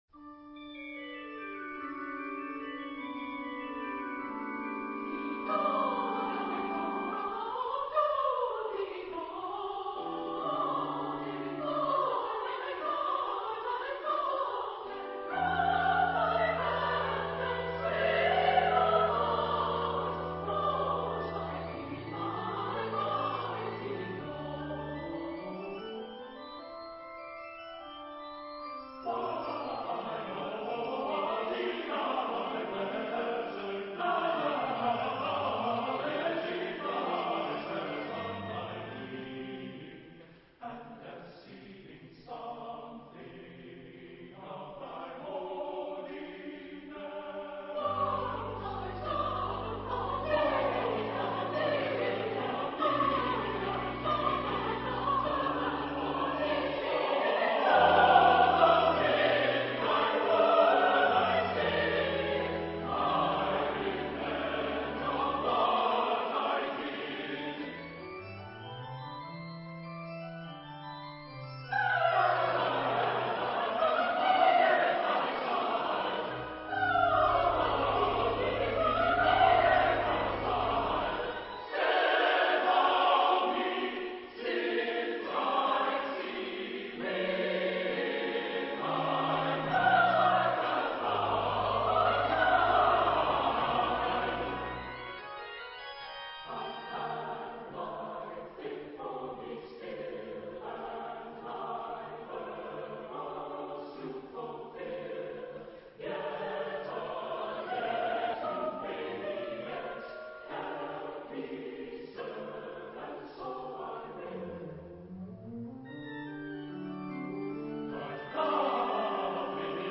Genre-Style-Forme : Sacré ; Motet
Type de choeur : SATB  (4 voix mixtes )
Instrumentation : Clavier
Instruments : Orgue (1)